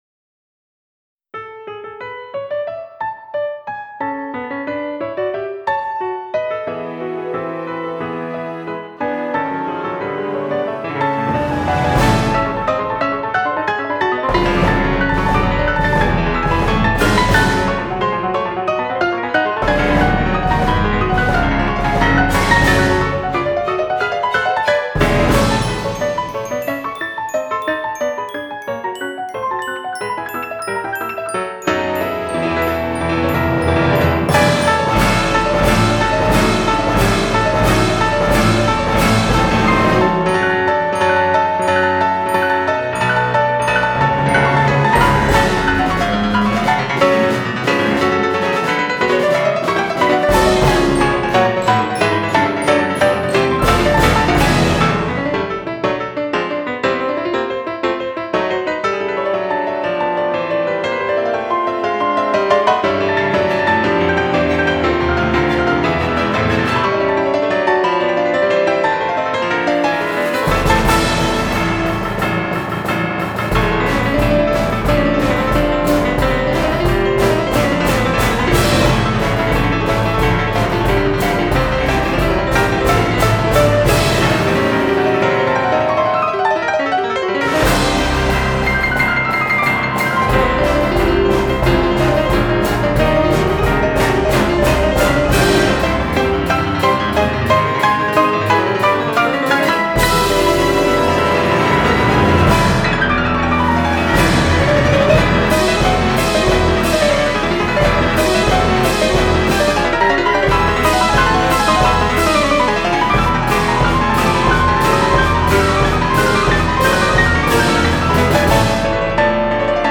BPM77-180
Audio QualityPerfect (High Quality)